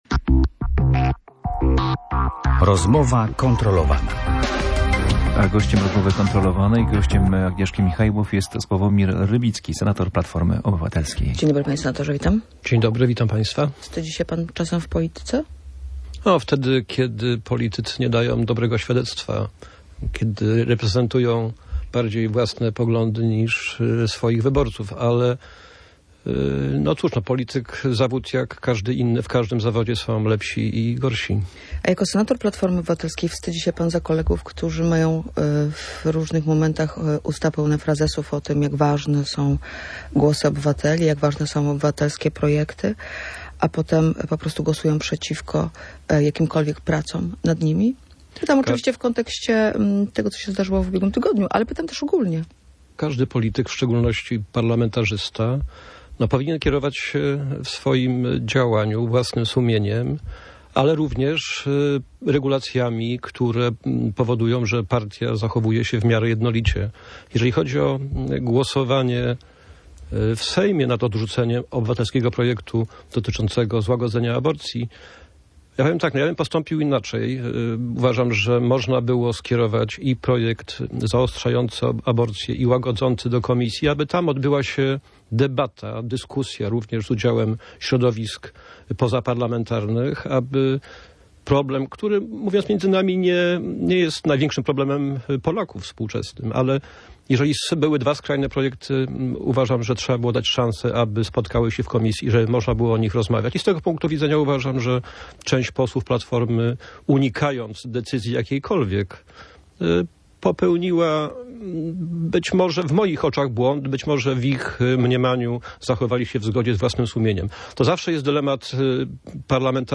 Polityk był gościem Rozmowy kontrolowanej.